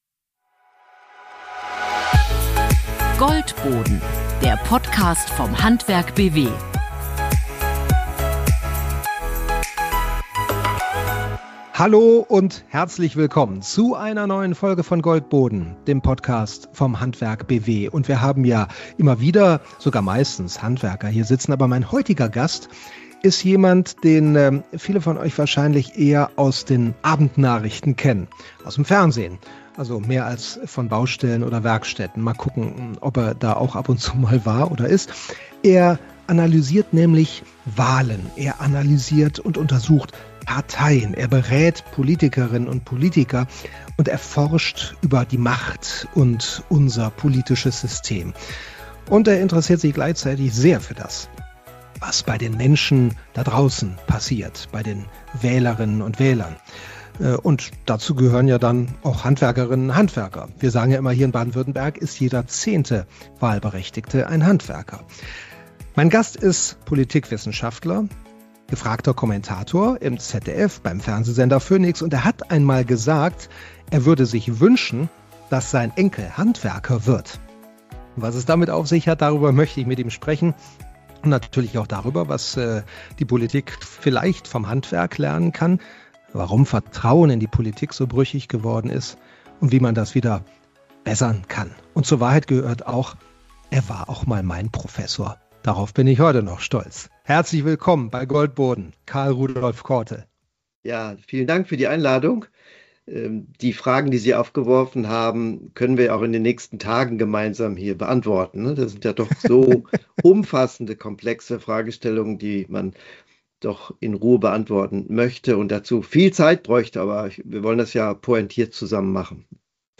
Ein Gespräch über Erklärmacht, Verantwortung und Nähe zur Lebenswirklichkeit.